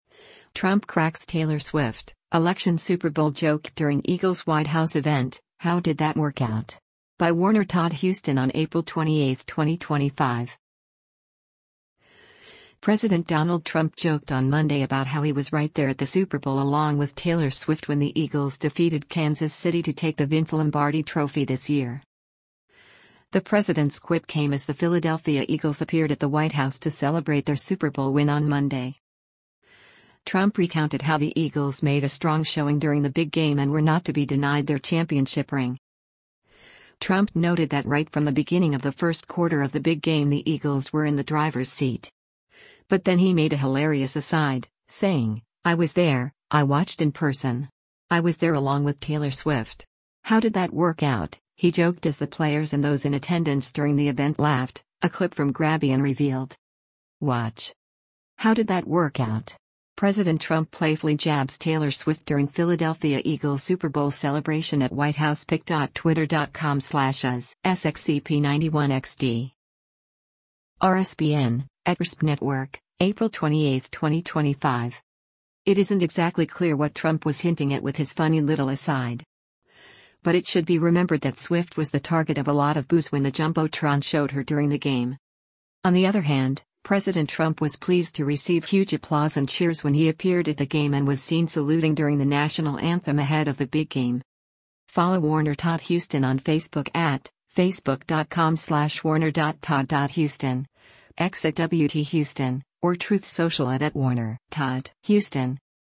The president’s quip came as the Philadelphia Eagles appeared at the White House to celebrate their Super Bowl win on Monday.
How did that work out,” he joked as the players and those in attendance during the event laughed, a clip from Grabien revealed.